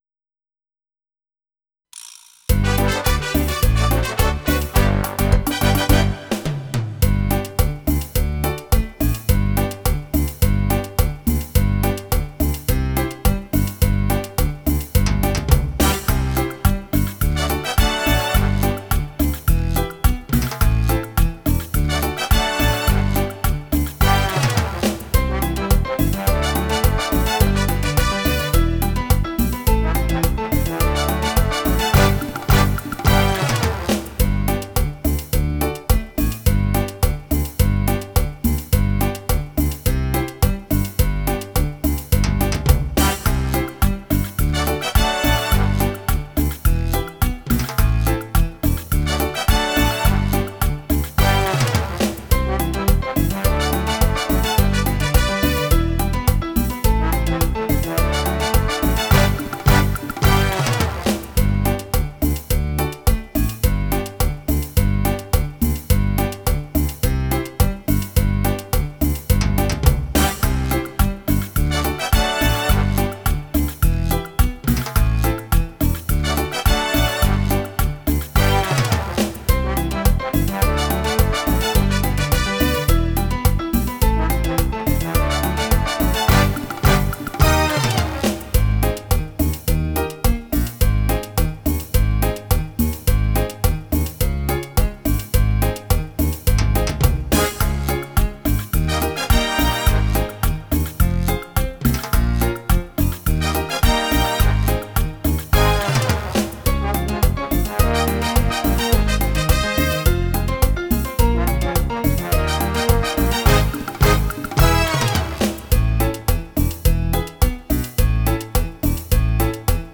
CUMBIA.wav